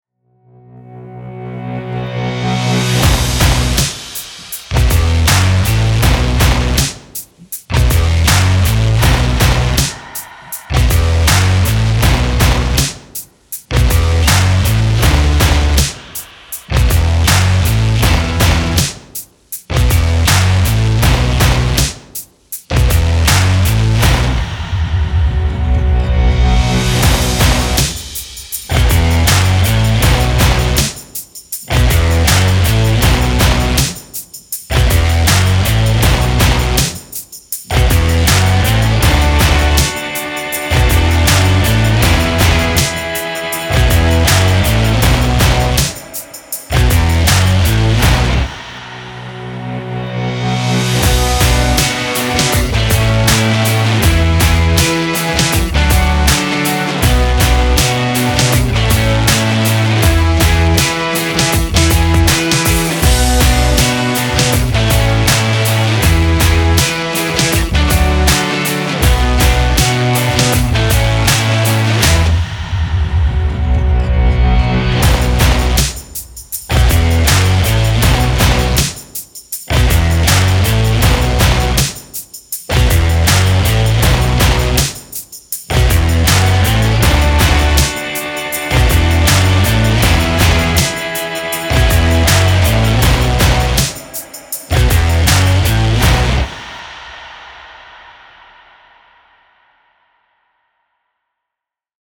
Освежающая атмосфера неприличных разговоров на тему "что у чудо-женщины под юбкой" и "почему Флэш может трогать всех, где захочет и никто этого не заметит" подует в ваше лицо, как легкий весенний ветерок, хотите вы того или нет.
Использована следующая музыка: